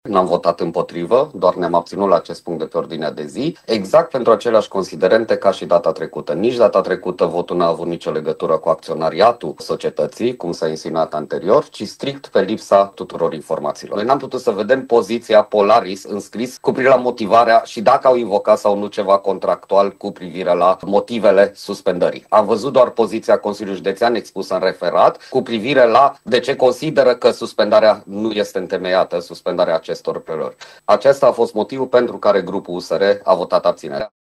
Consilierul județean Zoltan Nemeth a explicat motivele pentru care consilierii USR, acuzați că nu au susținut rezilierea, s-au abținut de la vot.